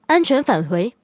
LT-35战斗返回语音.OGG